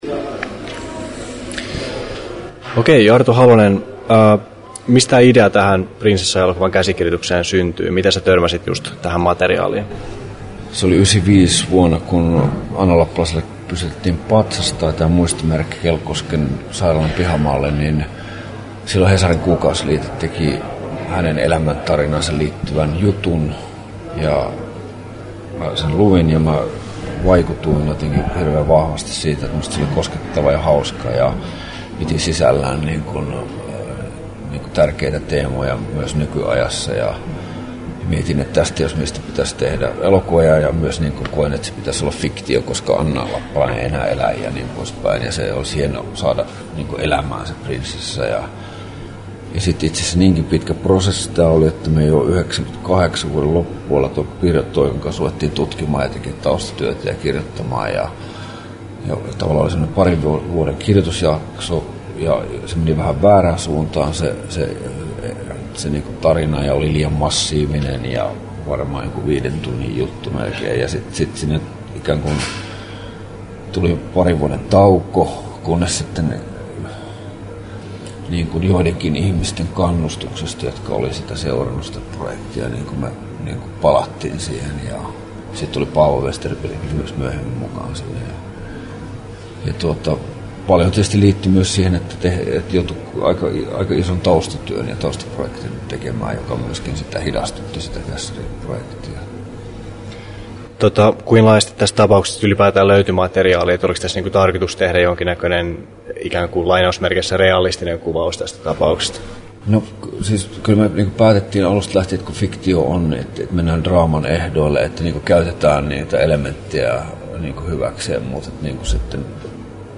Arto Halosen haastattelu Kesto: 11'10" Tallennettu: 2.9.2010, Turku Toimittaja